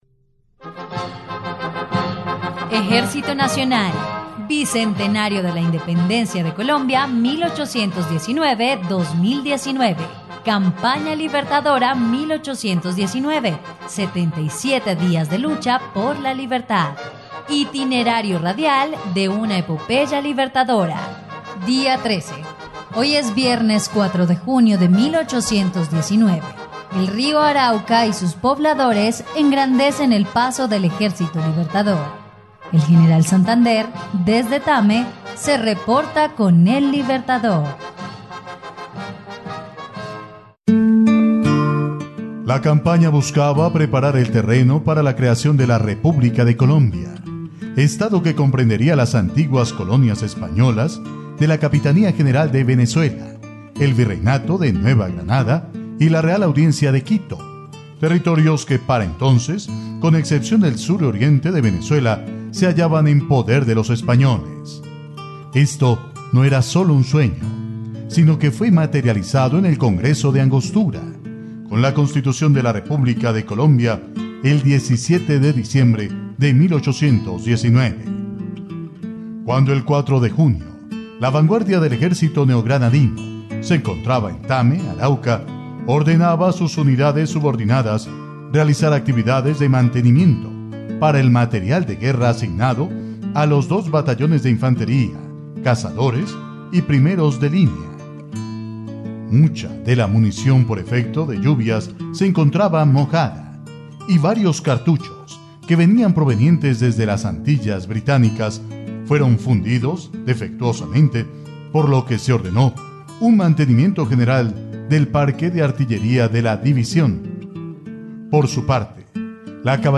dia_13_radionovela_campana_libertadora.mp3